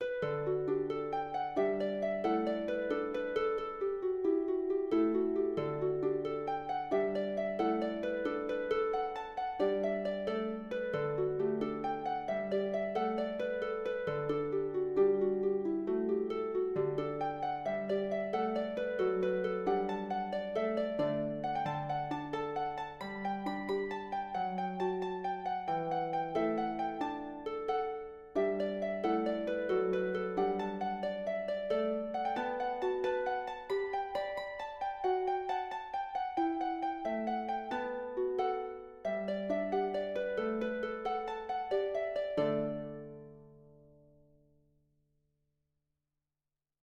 for the double-strung lever harp
(Traditional Scottish/Irish)